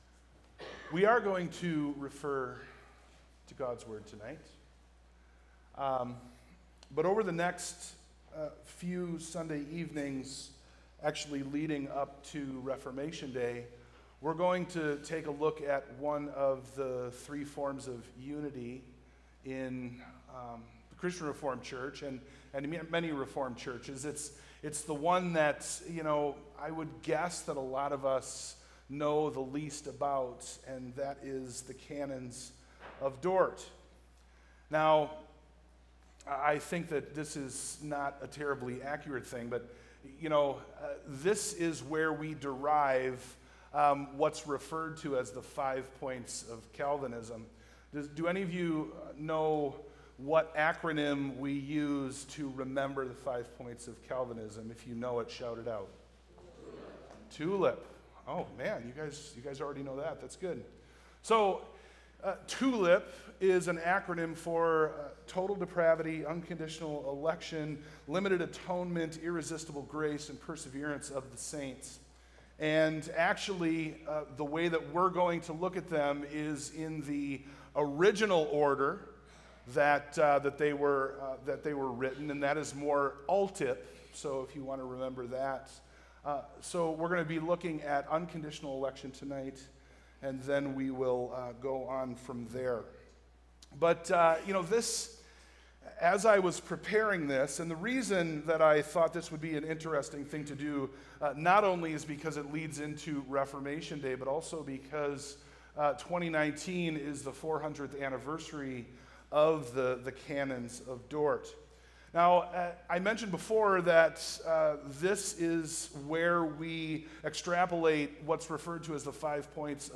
Sermon+Audio+-+Canons+of+Dort+-+Unconditional+Electrion.mp3